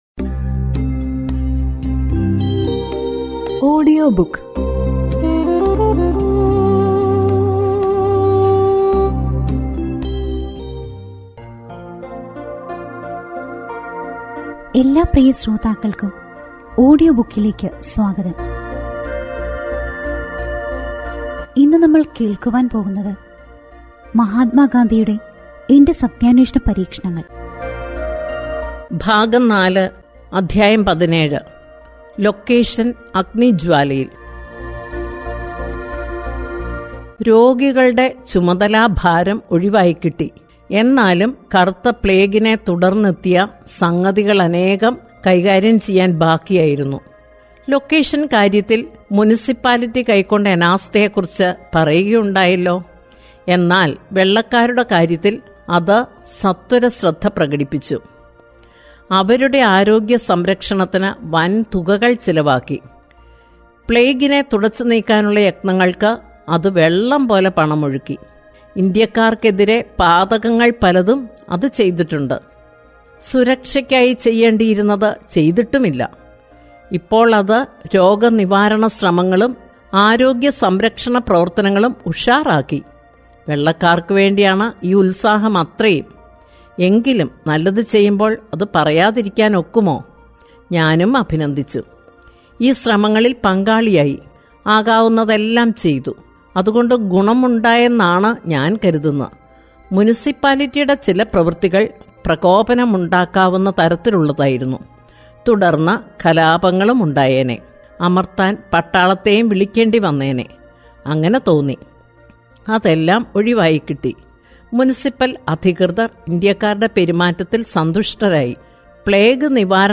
Audio Book